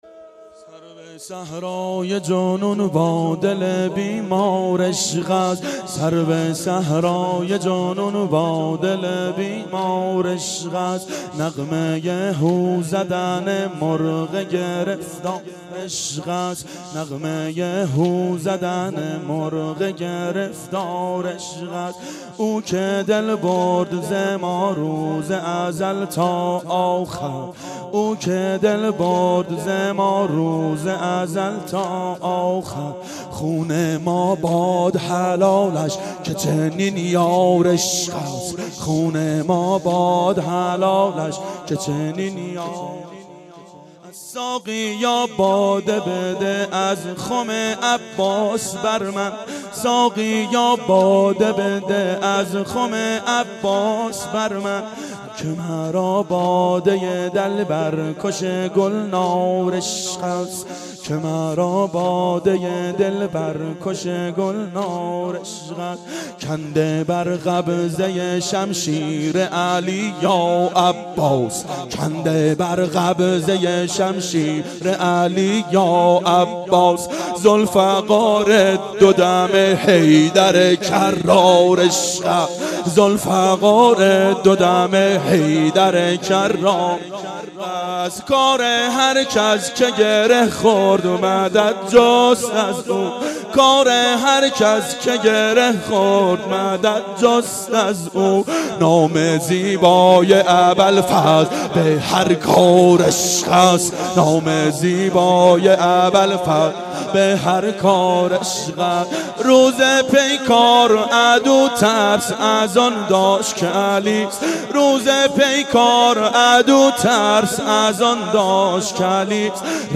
شب تاسوعا 92 هیأت عاشقان اباالفضل علیه السلام منارجنبان